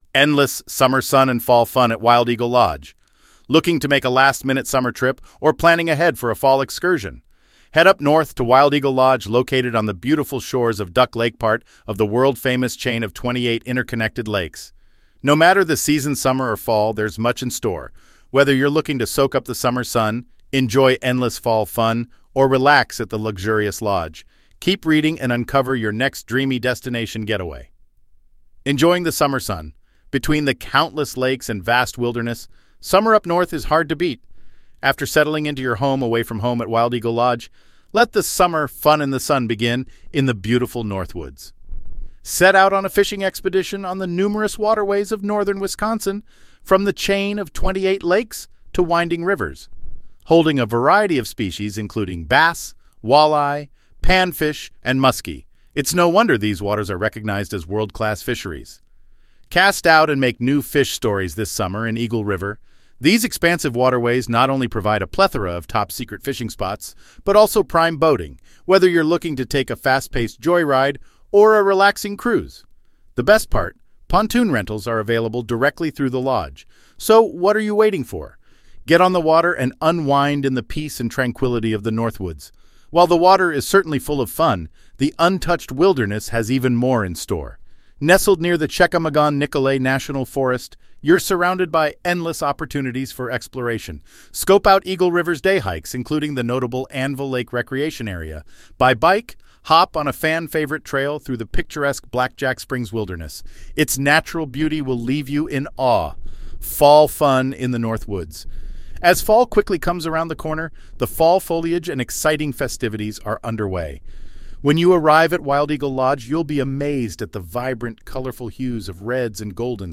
*Narration provided by Wondervox.
From the team at Discover Wisconsin: We believe in making our content accessible to all, which is why we offer an audio version of our blog posts.